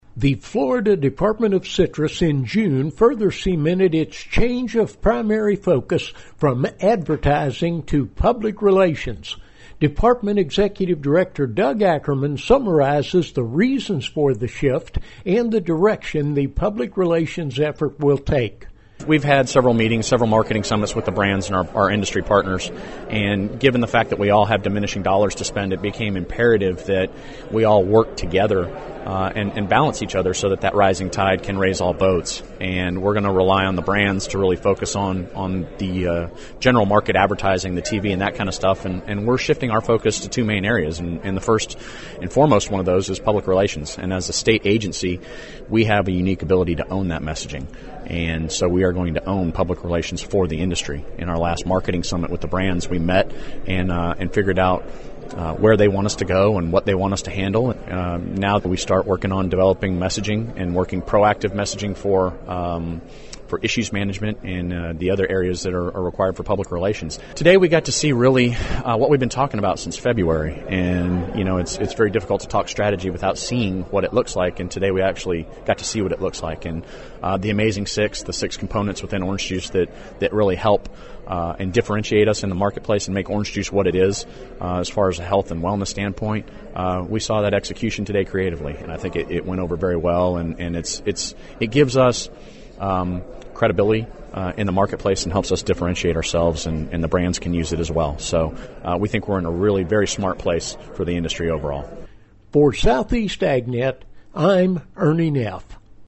Florida Department of Citrus Executive Director Doug Ackerman summarizes the department’s shift from advertising to public relations, a change that was cemented at the June meeting of the Florida Citrus Commission.